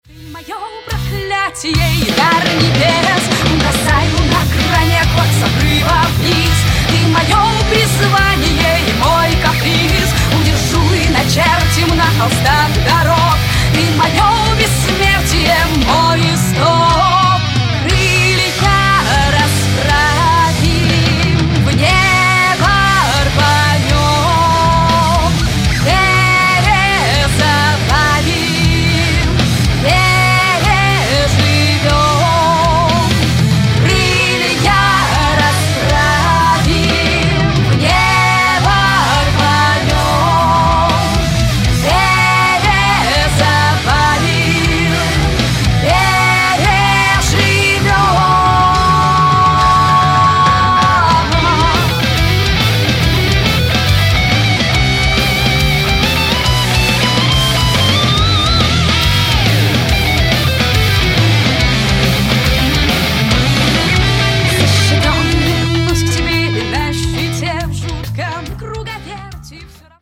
Ƹ���� ��������� � ���������� HARD ROCK track!